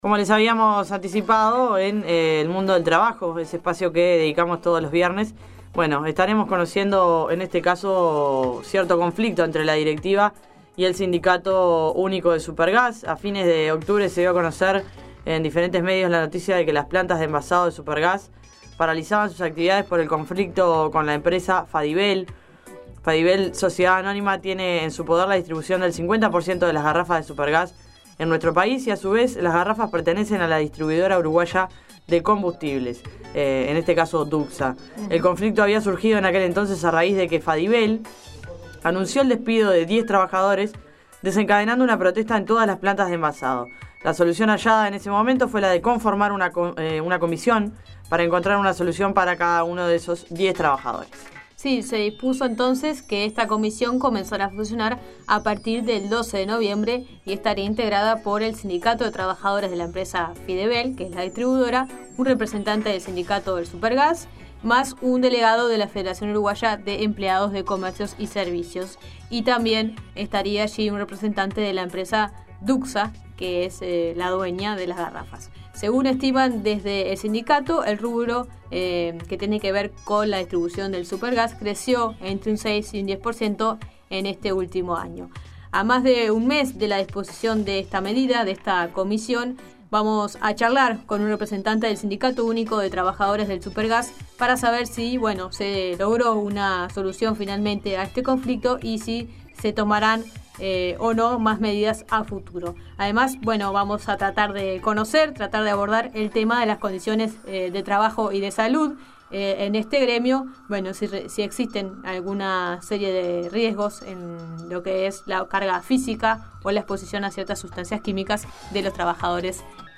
A más de un mes de la disposición de esta medida, charlamos con un representante del Sindicato Único de Trabajadores del Supergás.